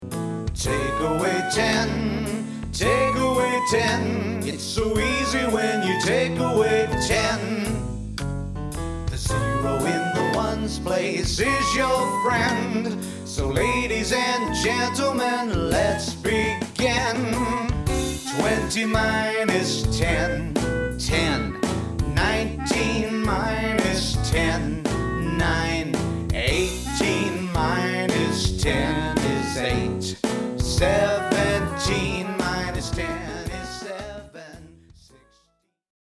"kid friendly" music